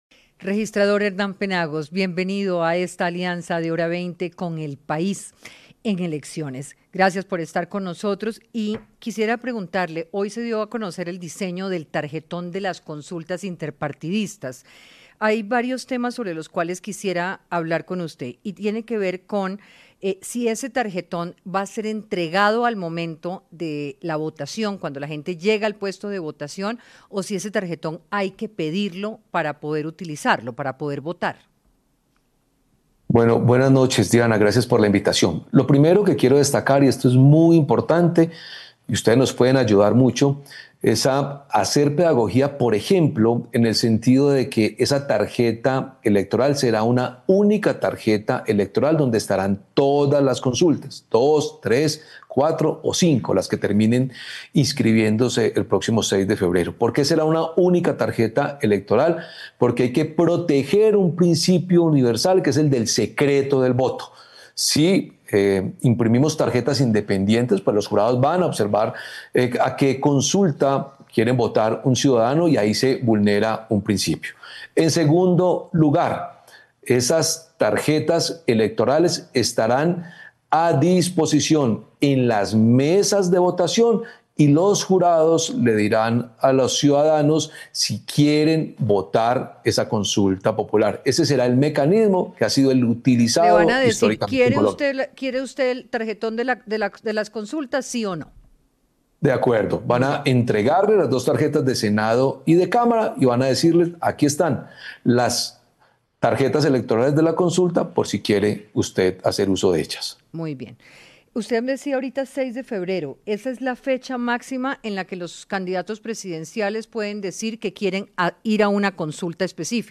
En diálogo con Hora20 y El País América en Colombia, el registrador general Hernán Penagos manifestó que en la revisión de las 28 millones de firmas presentadas por los grupos significativos de ciudadanos, fueron certificados 15 agrupaciones y seis descertificados, de los cuales, dos incurrieron en faltas como duplicados de firmas, que será denunciado ante las autoridades.